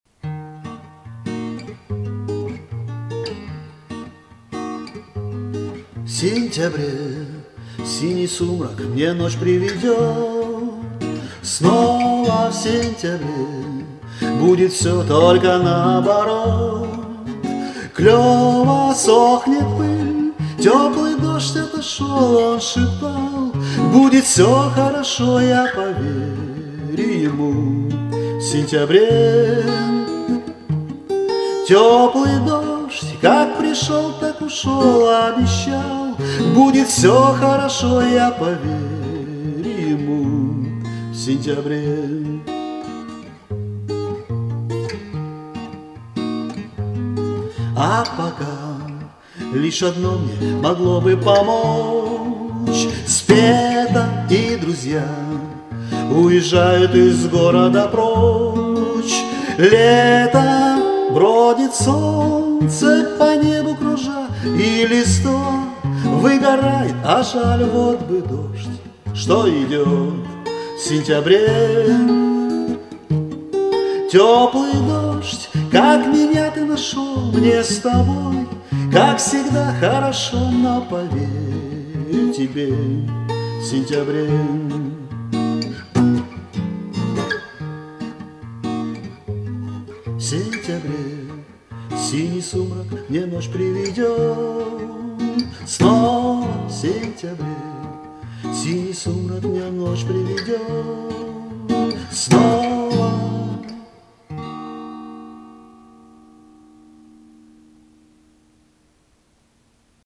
Рубрика: Поезія, Авторська пісня
Спасибо, было приятно, тепло и немножко дождливо! friends